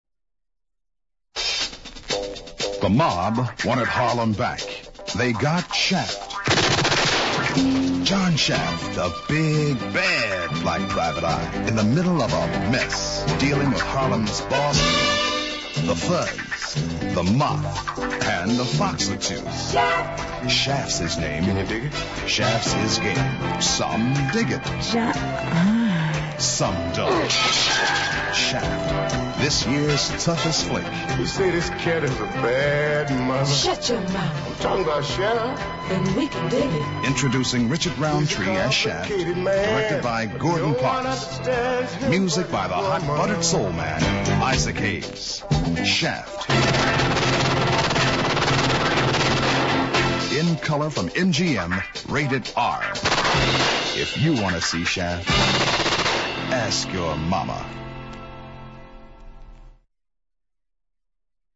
Radio spot